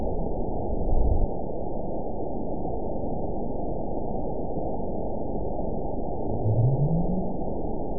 event 920350 date 03/18/24 time 03:10:23 GMT (1 year, 3 months ago) score 9.68 location TSS-AB01 detected by nrw target species NRW annotations +NRW Spectrogram: Frequency (kHz) vs. Time (s) audio not available .wav